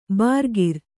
♪ bārgir